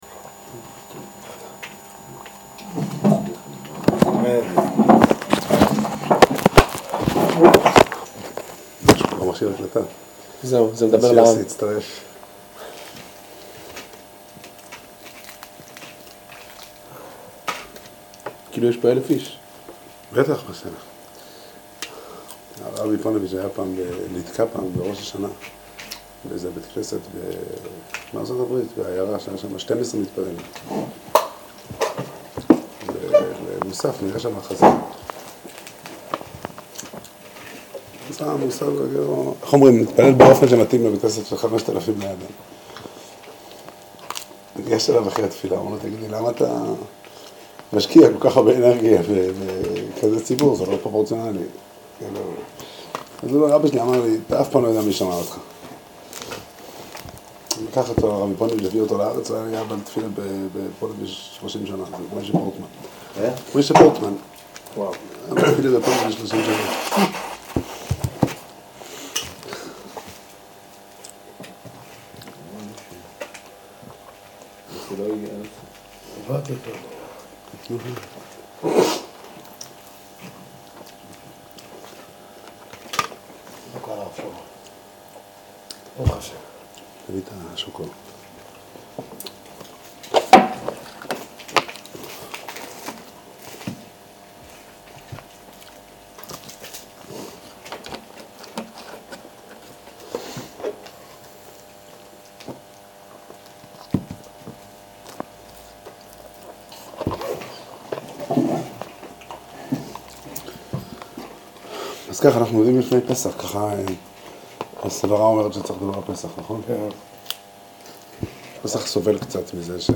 שיעור שנמסר לקבוצת תלמידים בתאריך ו' אדר תשס"ח